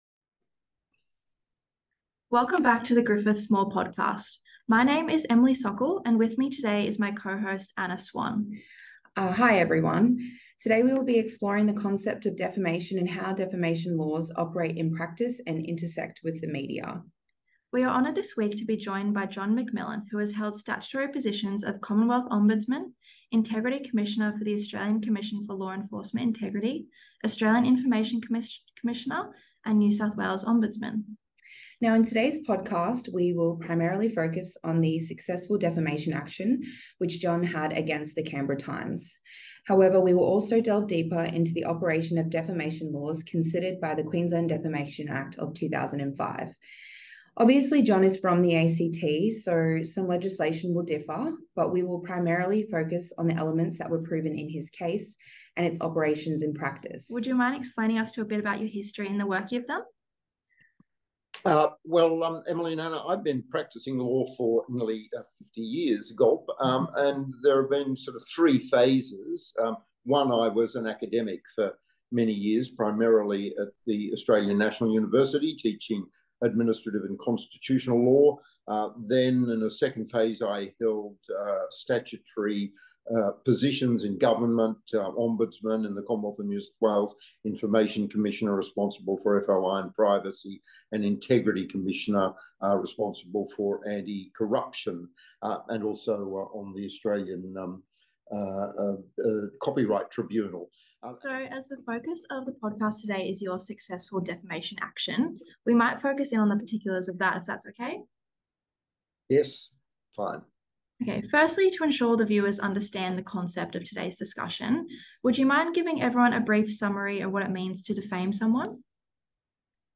Welcome to Griffith SMALL (Social Media And Law Livestream) where we interview experts on a range of aspects of social media law.